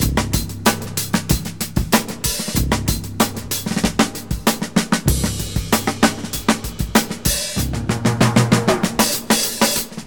127 Bpm Drum Loop C Key.wav
Free drum loop - kick tuned to the C note. Loudest frequency: 3211Hz
127-bpm-drum-loop-c-key-J9U.ogg